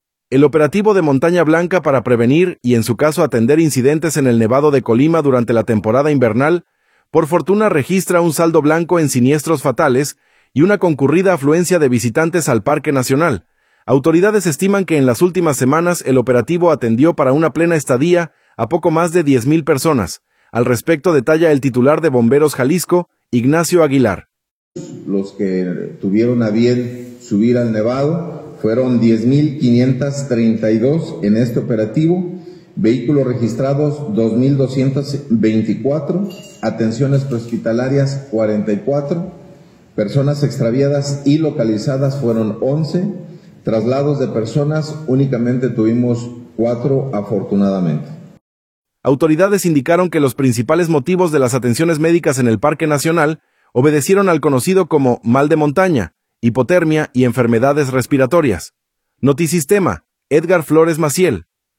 Autoridades estiman que en las últimas semanas el operativo atendió para una plena estadía a poco más de 10 mil personas. Al respecto detalla el titular de Bomberos Jalisco, Ignacio Aguilar.